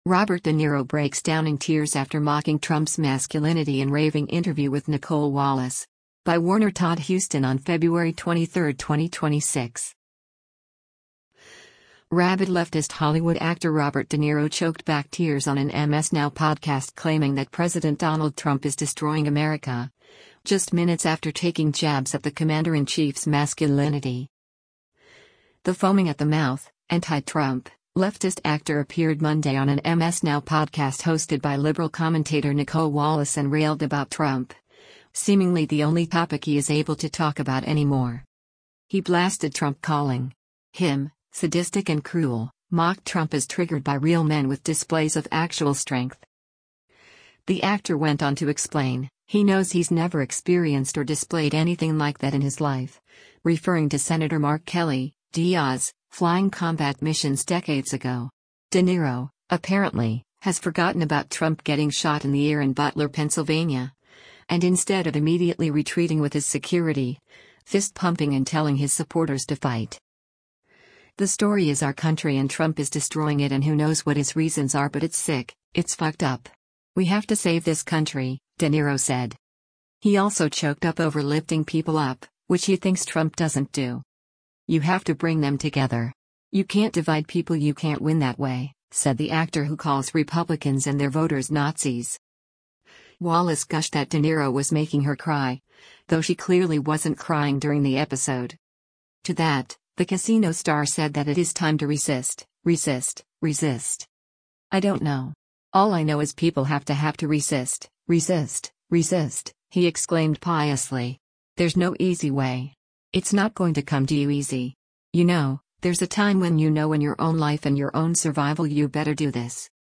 Robert de Niro Tears Up After Mocking Trump's Masculinity in Raving Interview
Rabid leftist Hollywood actor Robert De Niro choked back tears on an MS NOW podcast claiming that President Donald Trump is “destroying America” — just minutes after taking jabs at the Commander-in-Chief’s masculinity.
The foaming-at-the-mouth, anti-Trump, leftist actor appeared Monday on an MS NOW podcast hosted by liberal commentator Nicolle Wallace and railed about Trump, seemingly the only topic he is able to talk about any more.
He also choked up over “lifting people up,” which he thinks Trump doesn’t do.